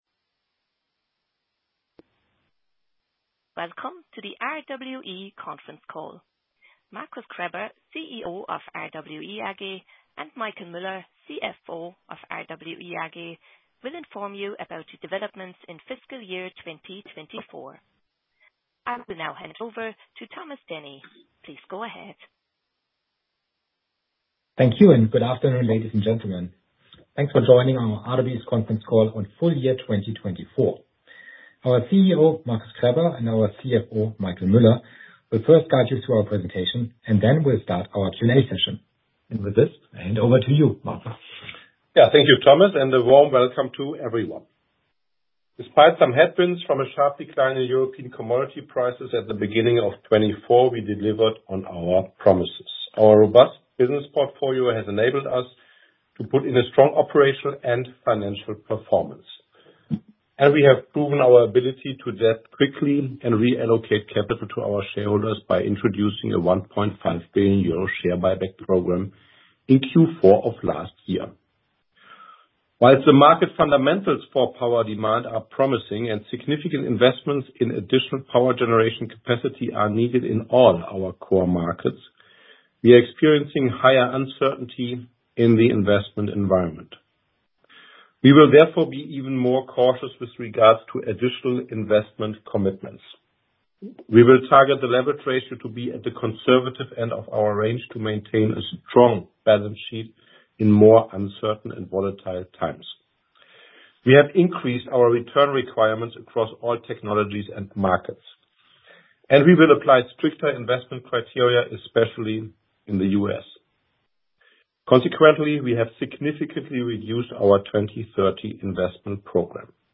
Investor and analyst conference call